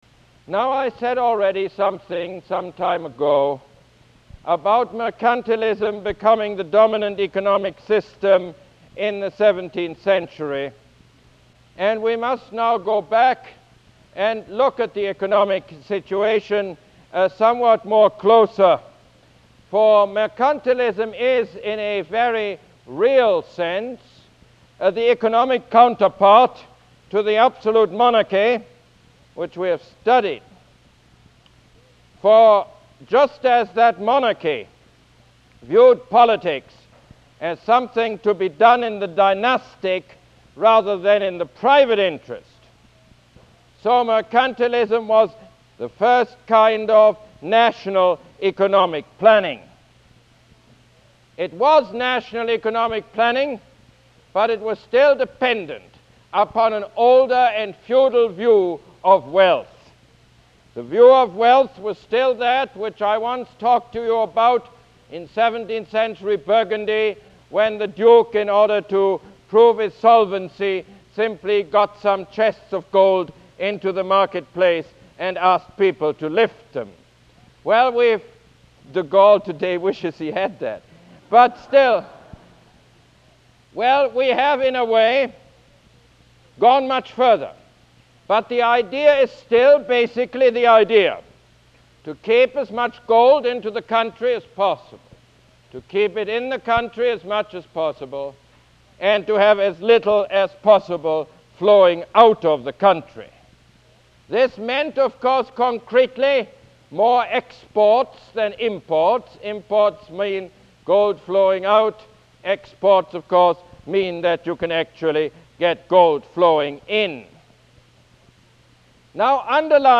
Lecture #17 - The Mercantilist System